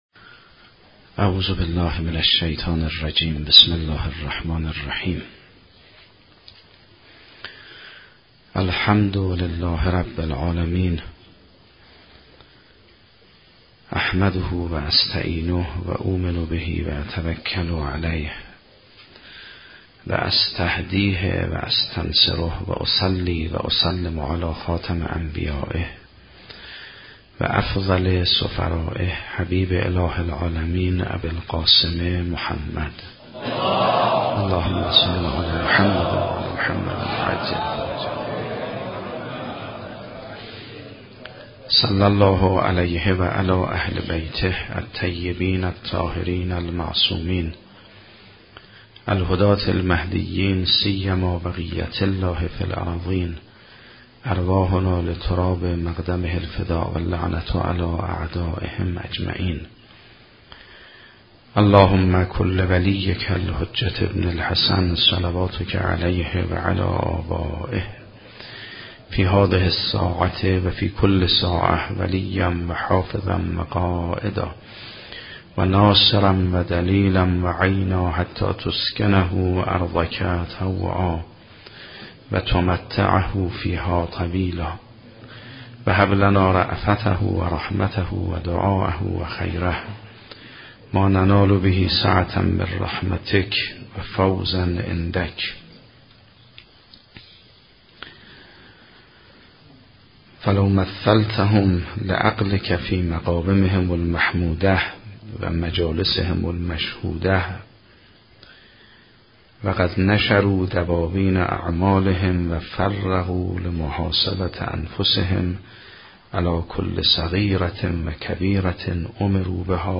متن زیر گزارشی تفصیلی از چهارمین جلسه سخنرانی آیت الله سیدمحمدمهدی میرباقری در دهه اول محرم 96 می باشد که در حسینیه حضرت زهراء سلام الله علیها